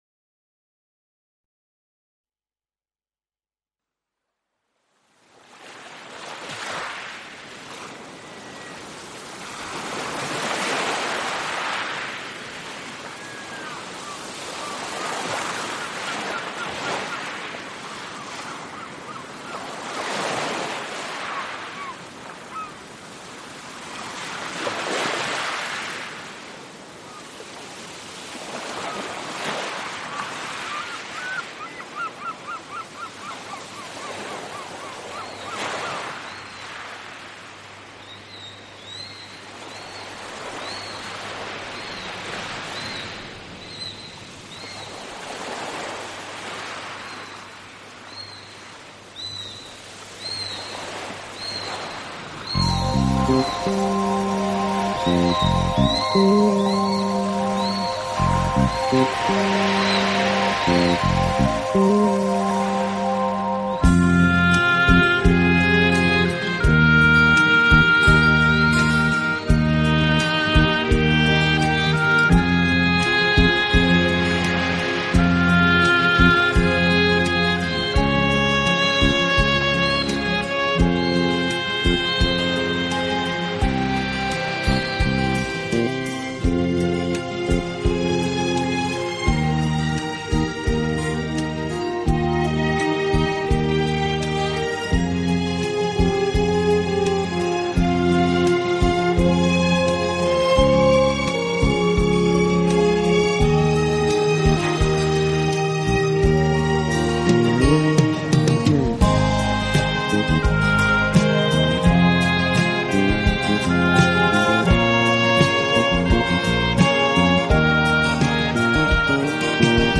自然聲響與音樂的完美對話
新世紀的音樂形式使躁動的靈魂得到最溫柔的撫慰，你將在夜裡
海浪、流水、鳥鳴，風吹過樹葉，雨打在屋頂，
大自然的原始採樣加上改編的著名樂曲合成了天籟之音。